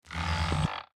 emp-coil-1.ogg